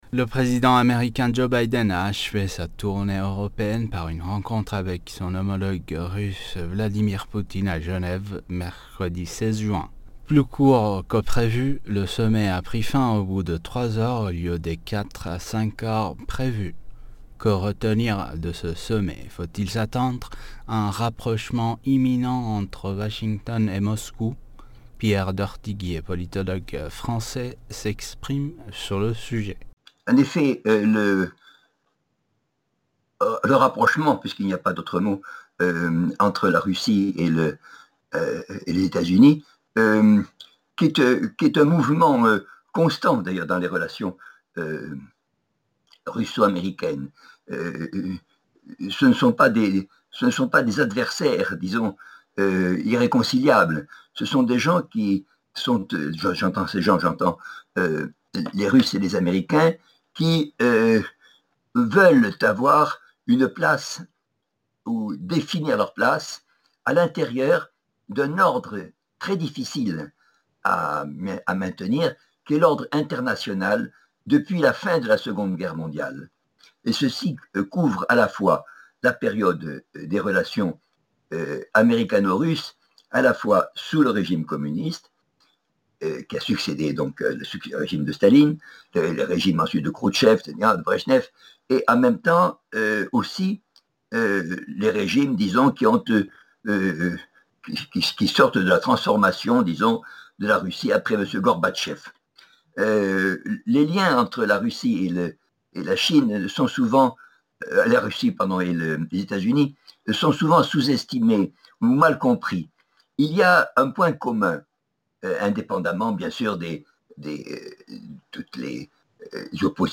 Mots clés International Russie usa interview Eléments connexes Quel rôle jouent les éléments saboteurs et terroristes dans les troubles en Iran ?